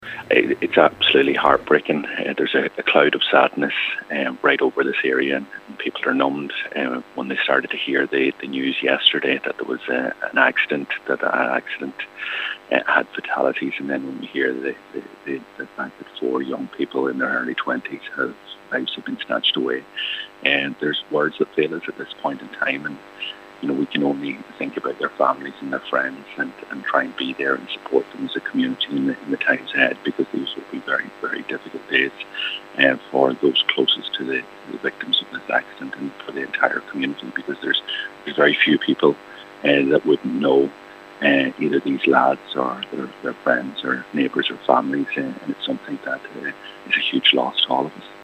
Donegal Deputy Pearse Doherty says during this time of great loss, support from the community is vital: